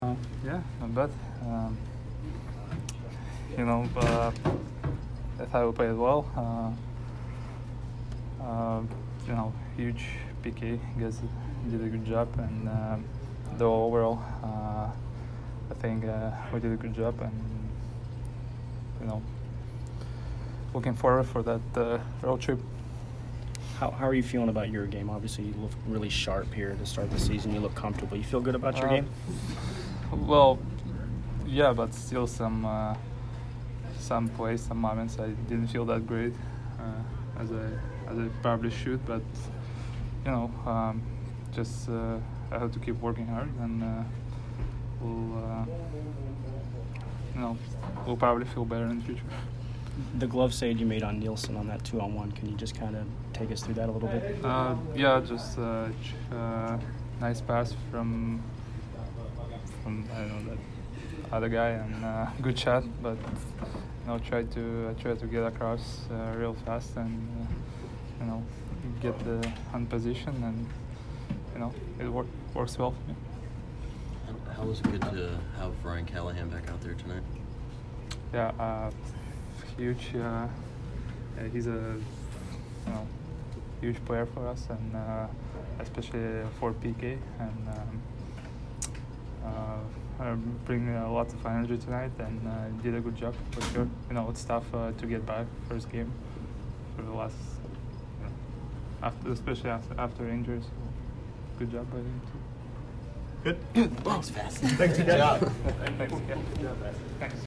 Andrei Vasilevskiy post-game 10/18